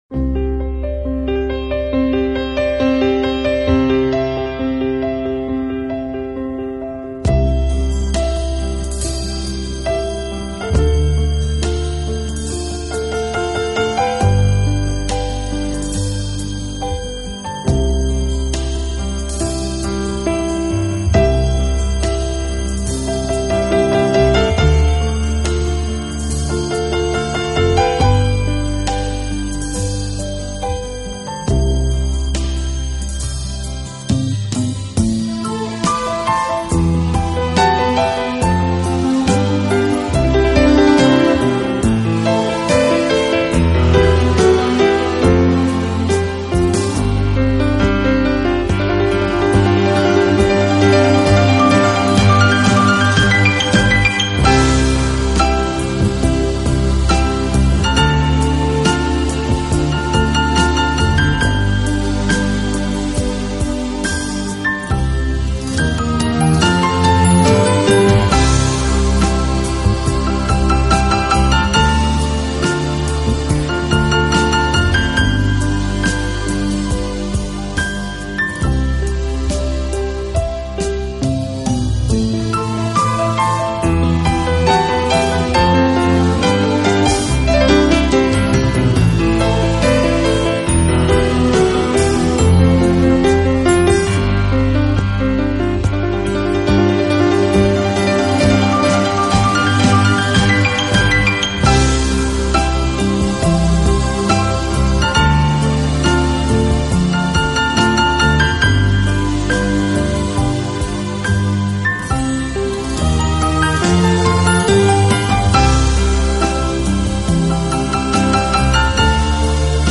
Genre: Instrumental/Classical/Easy Listening